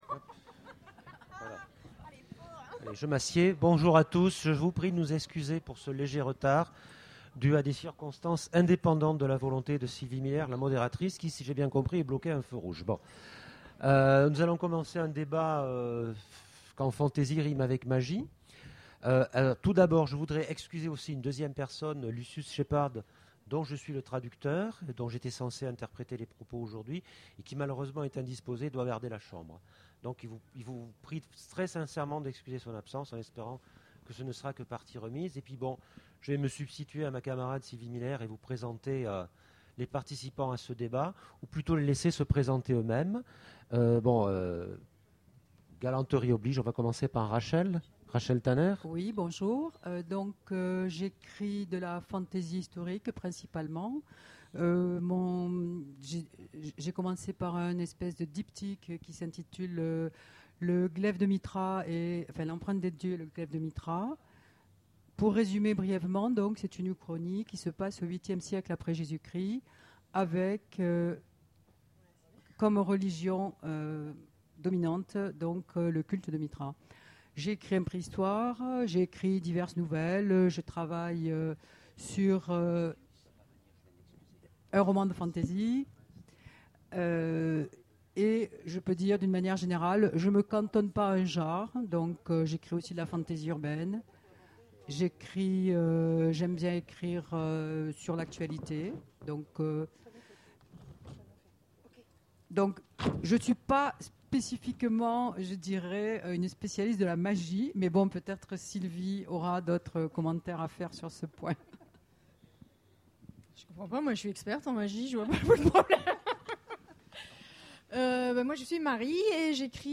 Imaginales 2013 : Conférence Quand fantasy rime avec...